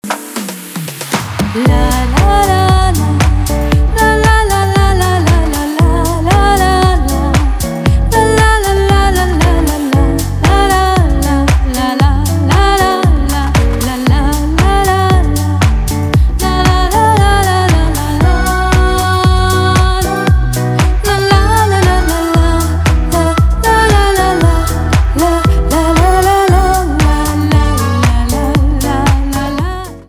танцевальные
битовые , гитара
красивый женский голос